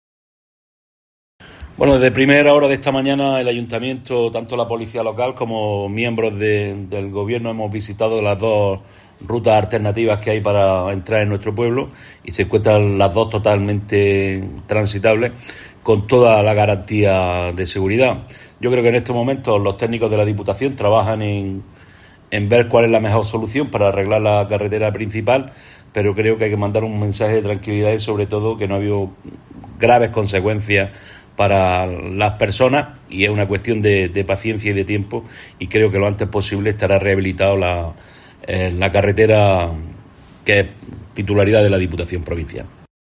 Jose Robles, alcalde de Güéjar Sierra